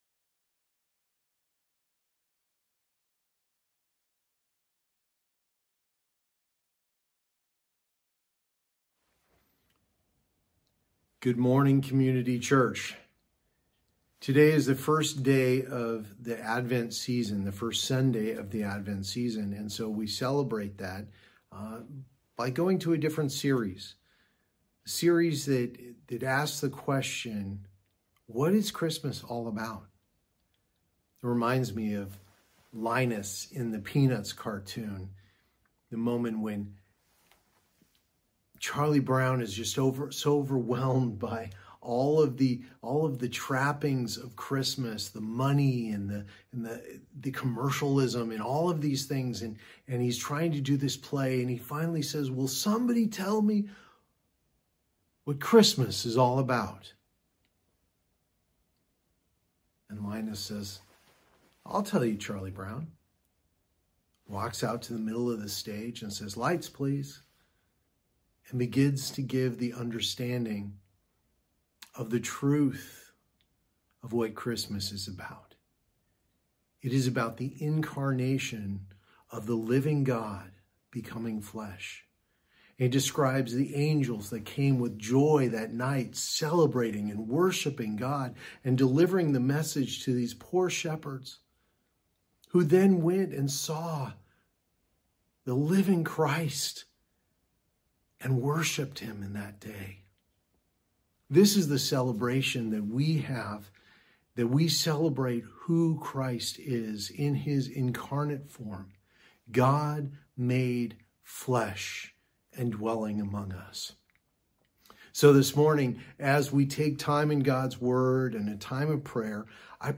Passage: Isaiah 7:14; 9:6-7 Services: Sunday Morning Service Download Files Notes Topics: Advent Previous Next